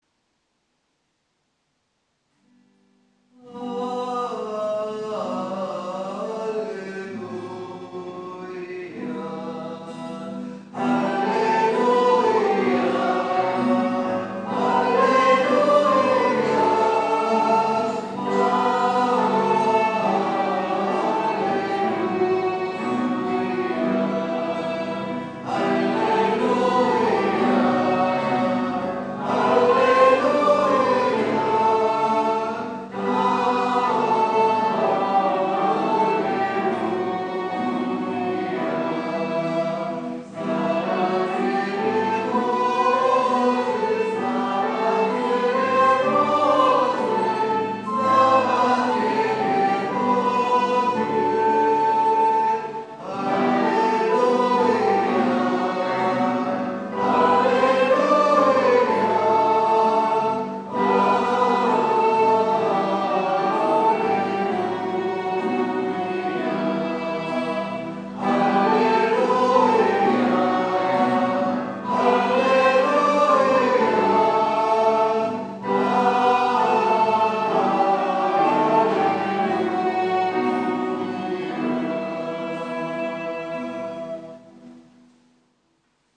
Pregària de Taizé
Ermita de Sant Simó - Diumenge 26 de gener de 2014